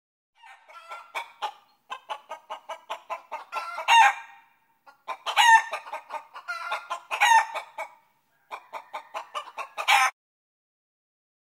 Chicken Clucking Sound
animal
Chicken Clucking